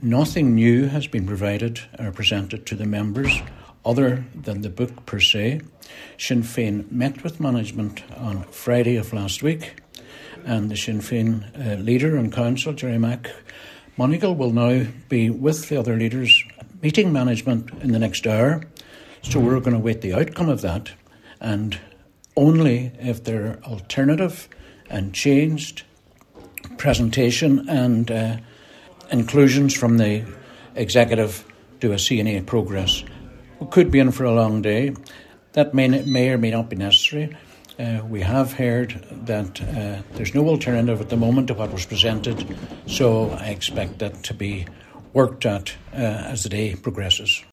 Cllr Doherty was speaking after the reconvened budget meeting adjourned immediately to allow for discussions between senior officials and party leaders.